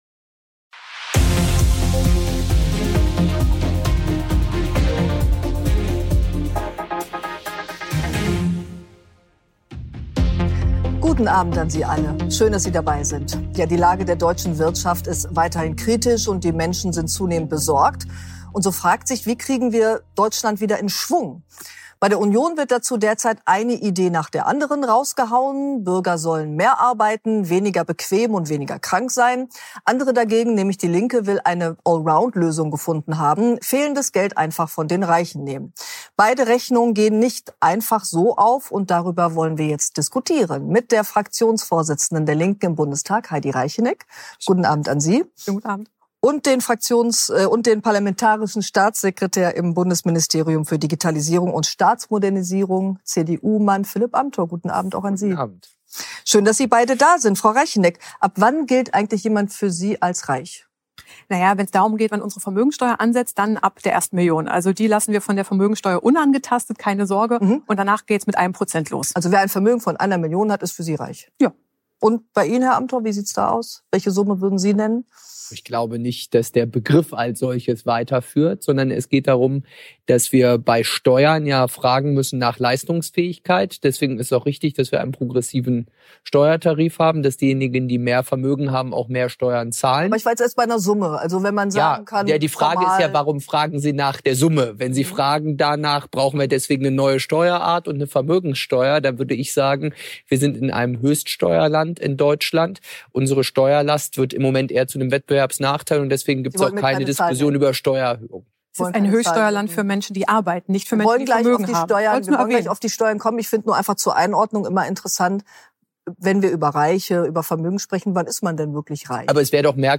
Amthor und Reichinnek - junge Ost-Politiker im Schlagabtausch
Zwei junge Politiker aus Ostdeutschland, zwei gegensätzliche Antworten auf dieselbe Frage: Wachstum oder Umverteilung? Bei "Pinar Atalay" geraten Heidi Reichinnek, die Vorsitzende der Linken-Fraktion im Bundestag, und Philipp Amthor, parlamentarischer Staatssekretär im Digitalministerium, gehörig aneinander. Es geht außerdem um Social-Media-Regeln und den Umgang mit der AfD.